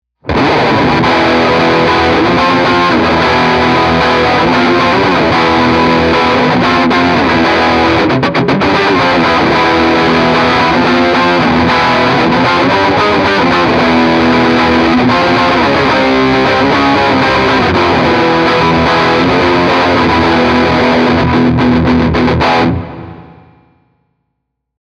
Tutte le clip audio sono state registrate con testata a valvole artigianale da 15W ispirata al Cornell Romany e cassa 1×12 equipaggiata con altoparlante Celestion A-Type impostato su un suono estremamente clean.
Chitarra: Gibson Les Paul (pickup al ponte)
Turbo: ON
Engine: 10/10